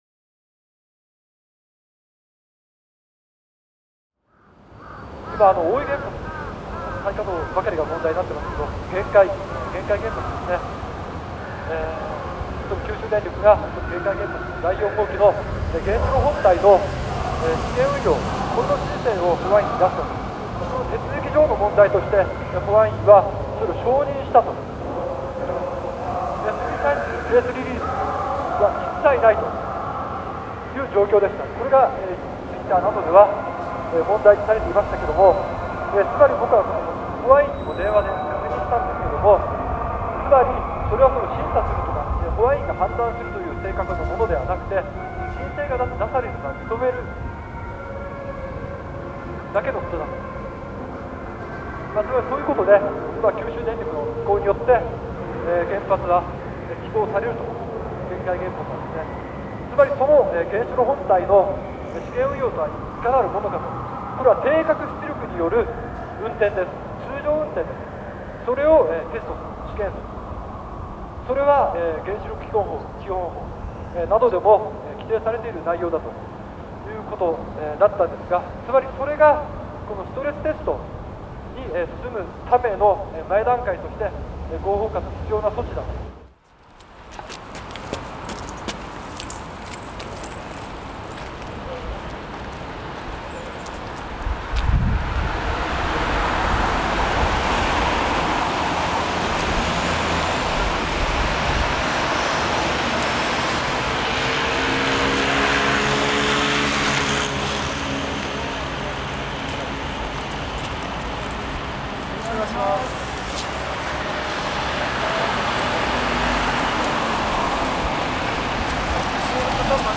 10:22 MP3 „311 silent Sendai“ is the demonstration against nukes and TPP in Sendai, Miyagi Japan 11 Mar. 2012 without any sounds; calling, music instruments and so on. Sendai city is 95km far from Fukushima Daiichi Nuclear Power Plant which have occurred melt through. The demonstration don’t sound at least, but there are very loud in the street.